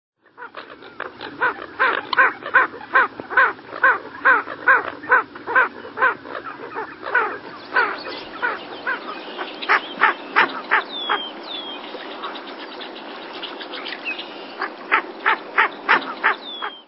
Ring-necked Duck
Bird Sound
Usually silent, courting calls soft and growly.
Ring-neckedDuck.mp3